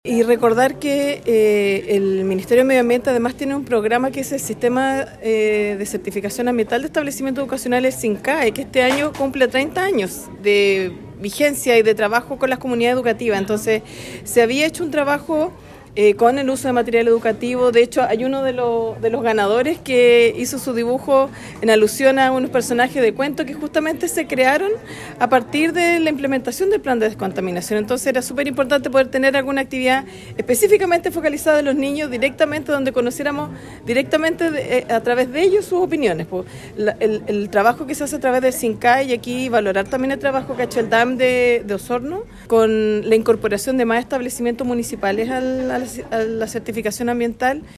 La Seremi señaló que esta actividad forma parte del sistema de certificación ambiental de los establecimientos educacionales de Osorno.